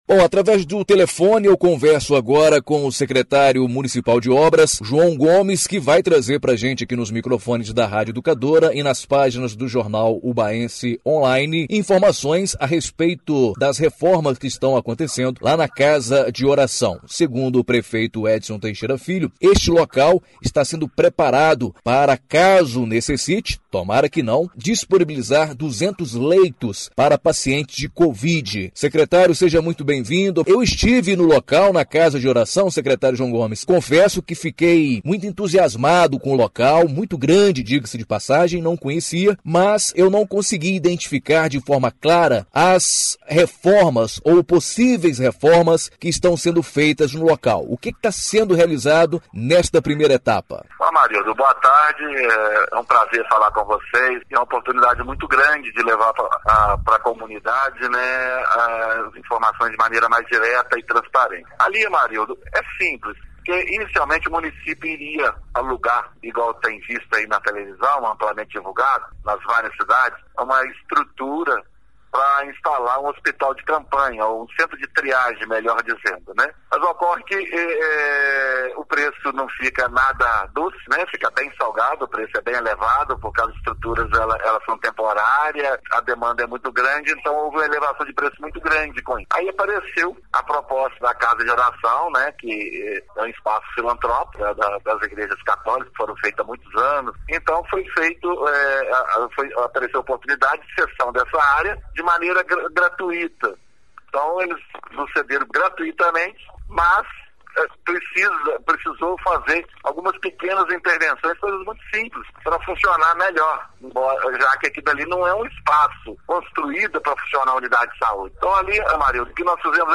Entrevista exibida na Rádio Educadora AM/FM